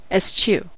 eschew: Wikimedia Commons US English Pronunciations
En-us-eschew.WAV